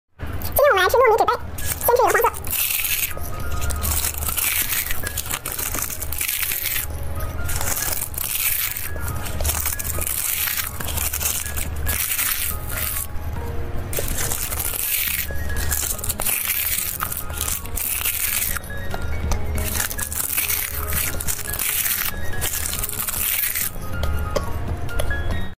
ASMR eating colourful paper cups sound effects free download